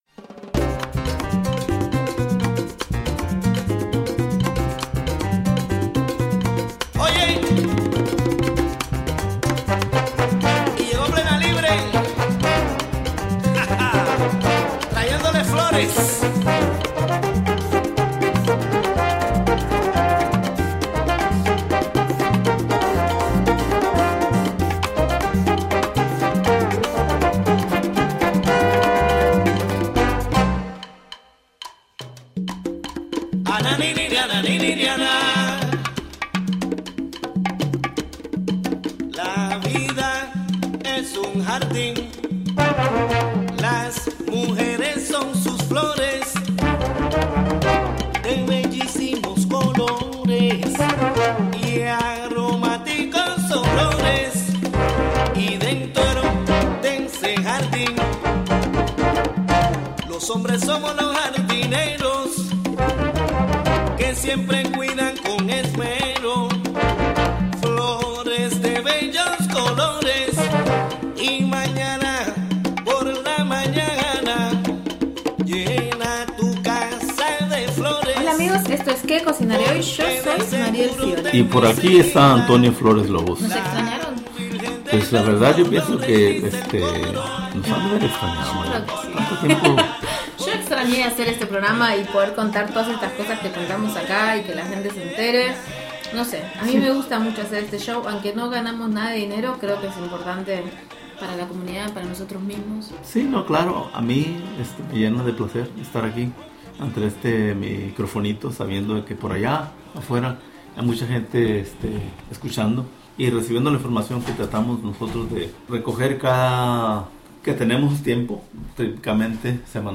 11am This weekly Spanish language radio news show retur...